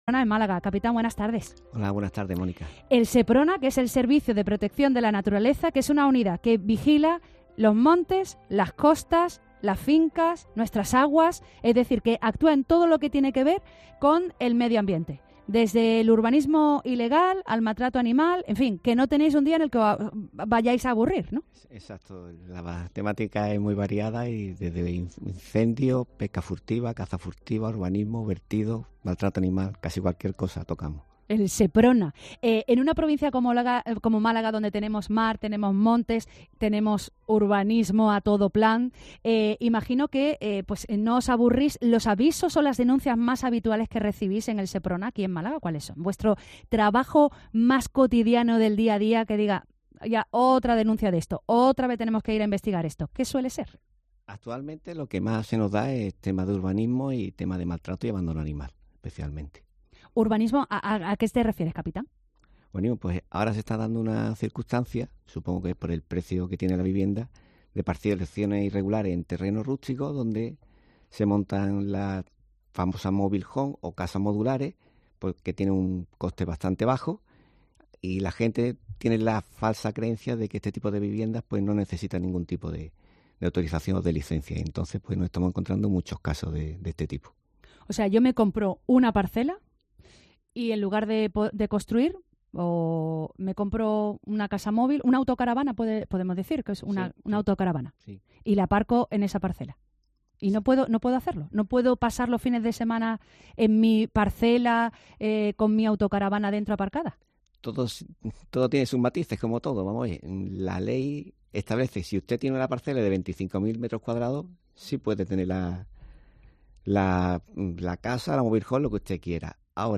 Entrevista sobre la labor del SEPRONA en Málaga y sobre los errores de compradores de casas móviles